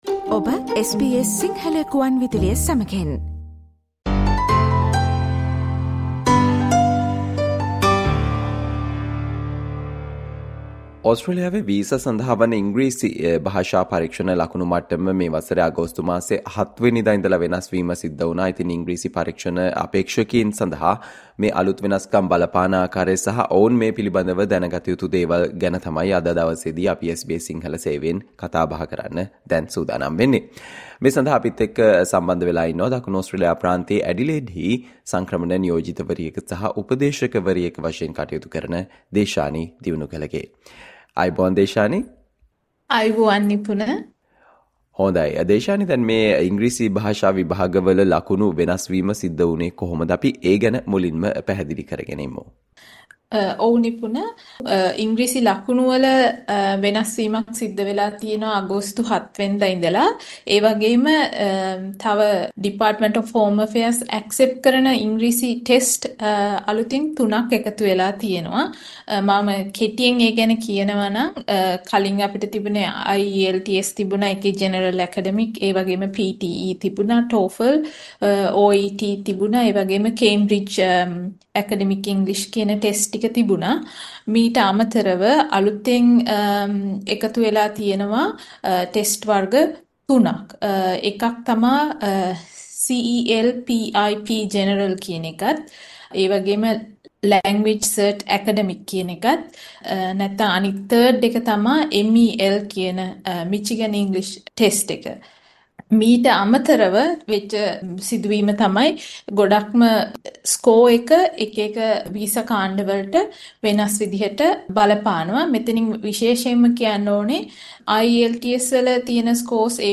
2025 අගෝස්තු 7 වන දින සිට PTE සහ IELTS ඇතුළු ඕස්ට්‍රේලියානු වීසා සඳහා වන ඉංග්‍රීසි ලකුණු මට්ටම වෙනස් වීම සිදු වූ අතර ඉංග්‍රීසි පරීක්ෂණ අපේක්ෂකයින් මෙම අලුත් වෙනස්කම් ගැන දැනගත යුතු දේ ගැන SBS සිංහල සේවය සිදු කල සාකච්චාවට සවන් දෙන්න